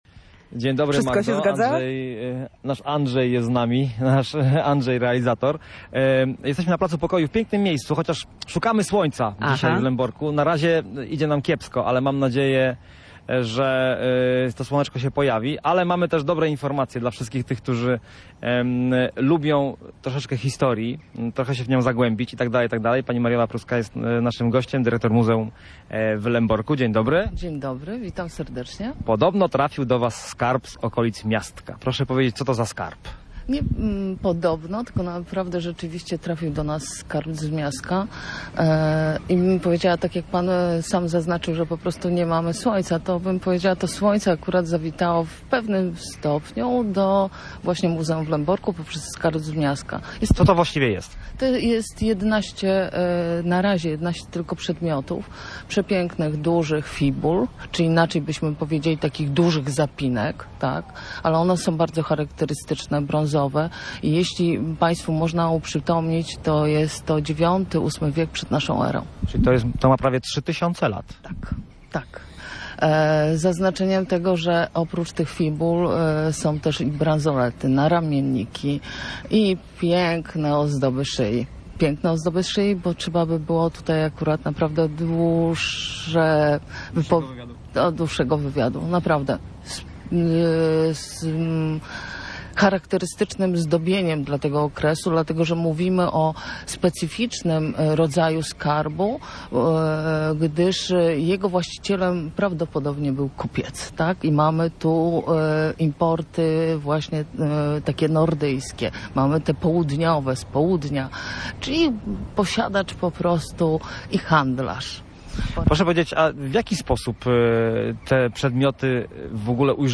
Lębork może poszczycić się nowymi skarbami i nowymi inwestycjami. O tym co przyciąga turystów do tego pomorskiego miasta, rozmawialiśmy z